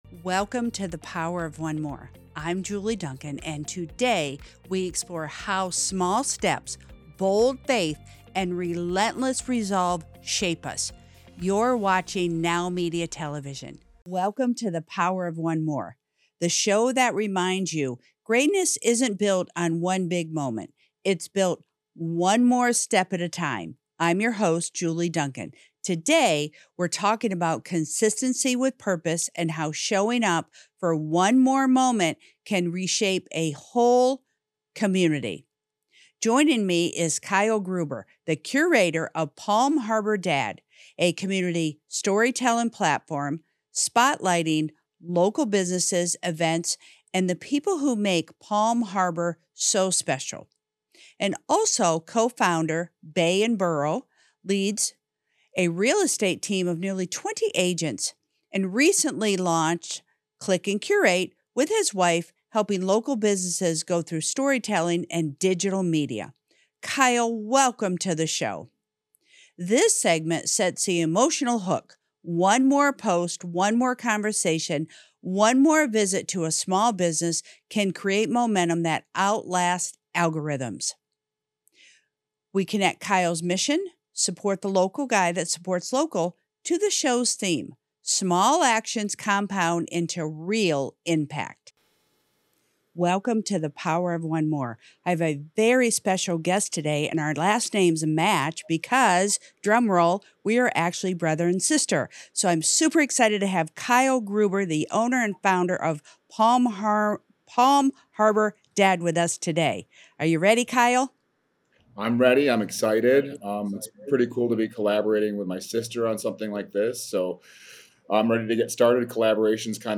This conversation focuses on consistency with purpose, storytelling, and how showing up daily can transform not only a business, but an entire local ecosystem.